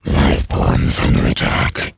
alien_lifeformattack1.wav